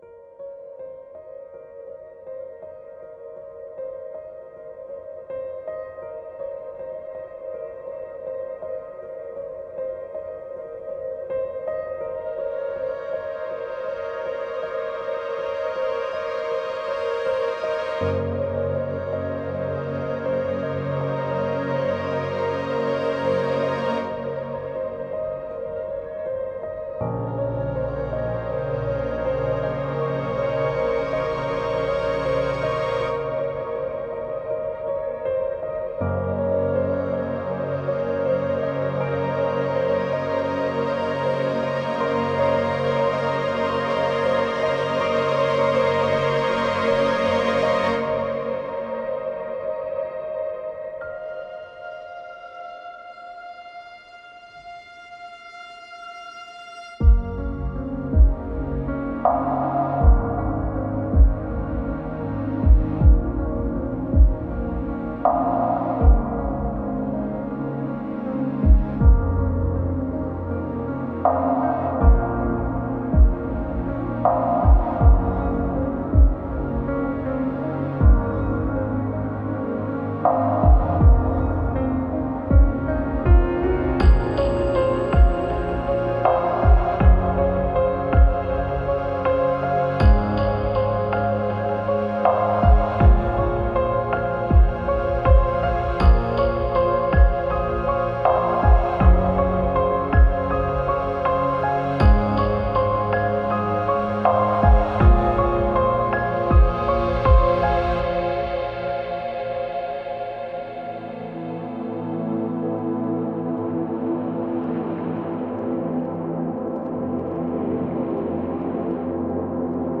孤独、内省、静かな緊張感をテーマに設計されており、有機的な感情とシネマティックな深みを融合させています。
デモサウンドはコチラ↓
Genre:Ambient